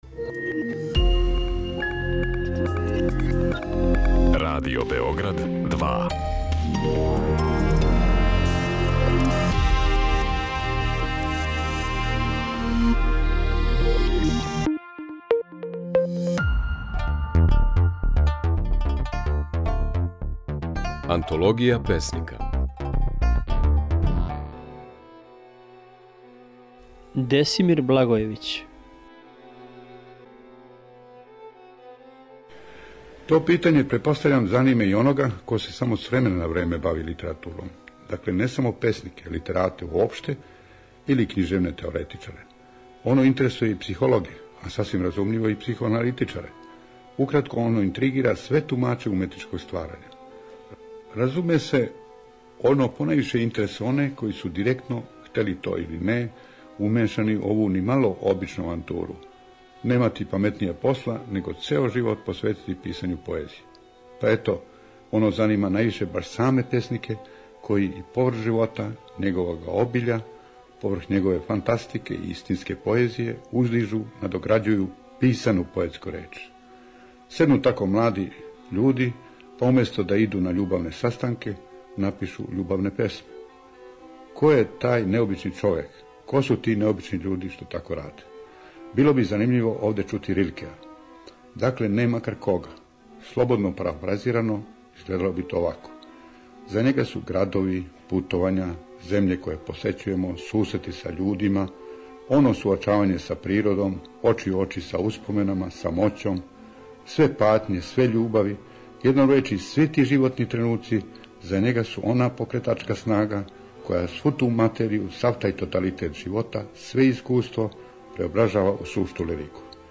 Слушаћемо како је своје стихове говорио песник Десимир Благојевић (1905-1993).